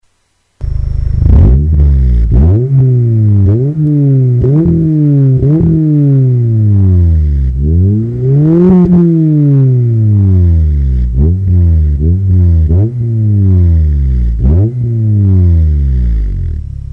7. Ausbaustufe: 70mm Komplettanlage ab KAT, 2x90mm Endrohr, Vertrieb TSS-Tuning (10/2004)
Auspuff Soundfile
MeinCTR-Auspuff.mp3